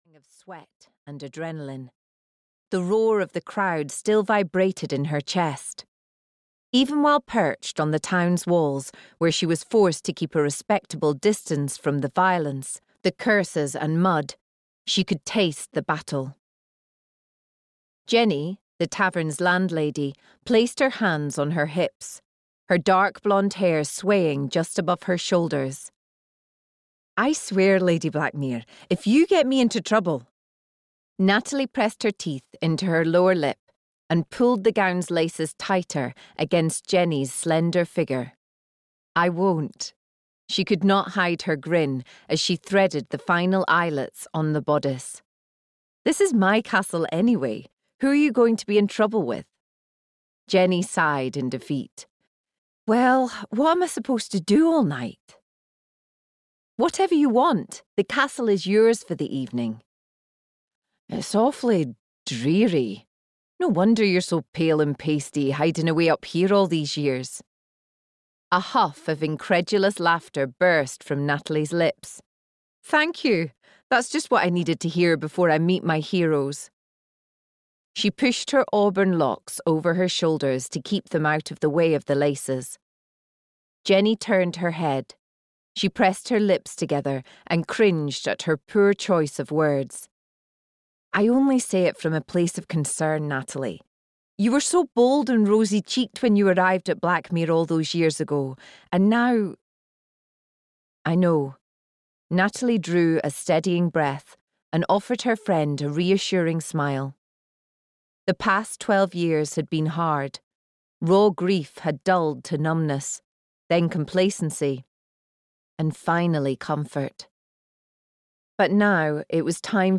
The Lady's Champion (EN) audiokniha
Ukázka z knihy